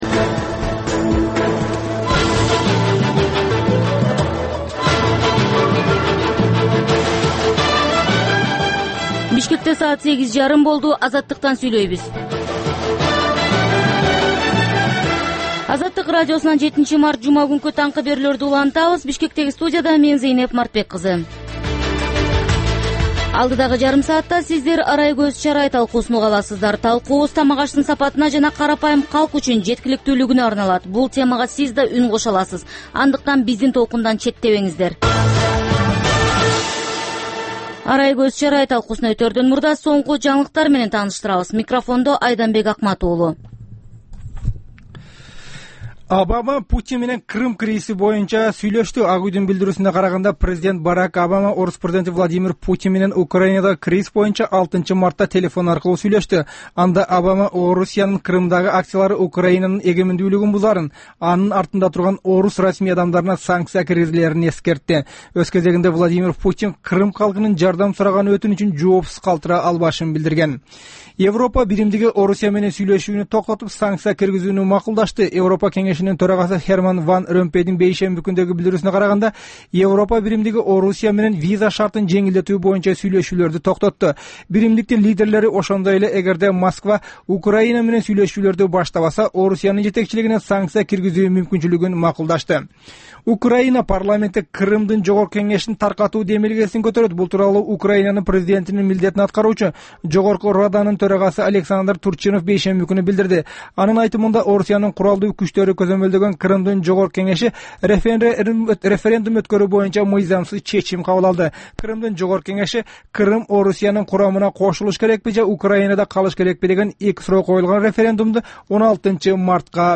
Бул таңкы үналгы берүүнүн 30 мүнөттүк кайталоосу жергиликтүү жана эл аралык кабарлар, ар кыл орчун окуялар тууралуу репортаж, маек, күндөлүк басма сөзгө баяндама, «Арай көз чарай» түрмөгүнүн алкагындагы тегерек үстөл баарлашуусу, талкуу, аналитикалык баян, сереп жана башка берүүлөрдөн турат.